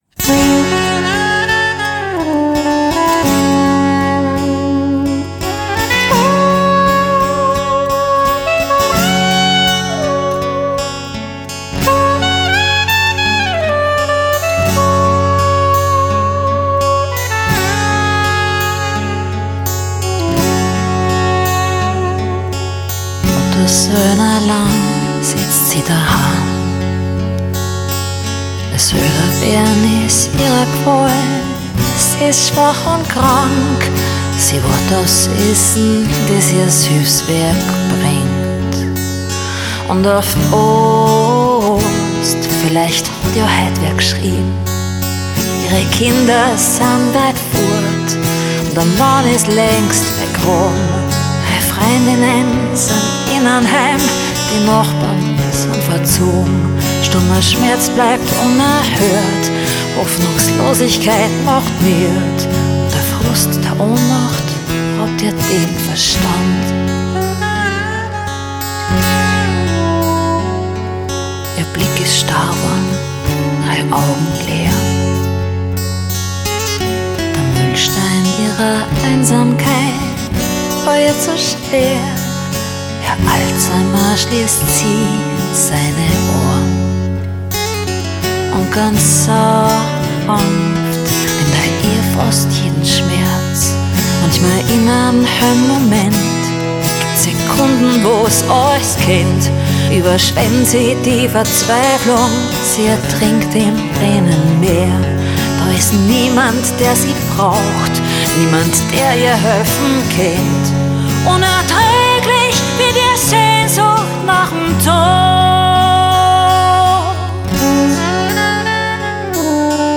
arr,sax